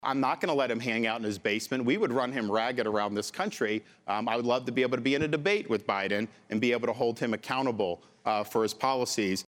During last night’s townhall, DeSantis directed most of his fire at President Joe Biden.